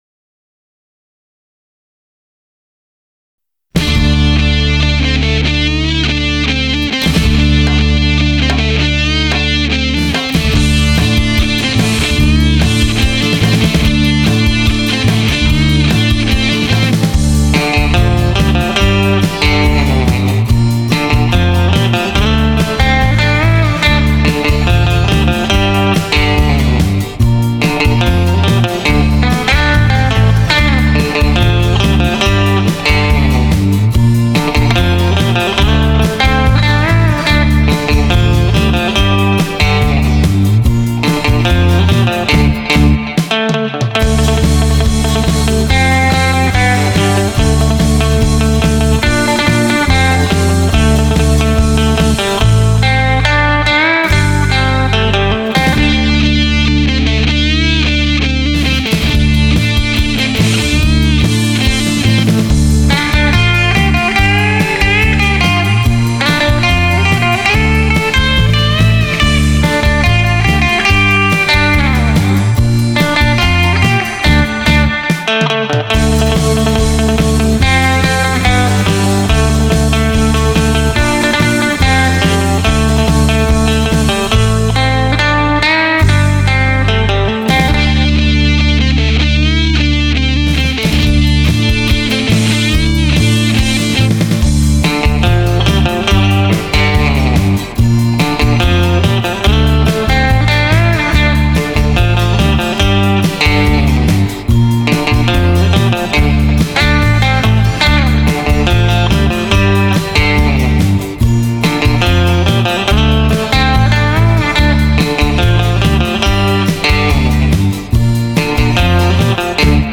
This is my new Fender Jazzmaster with the mods listed below.
Mojo Jazzmaster pickups
BTW-----Jazzmaster sounds great!!!